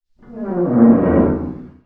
CREAK_Metal_Heavy_mono.wav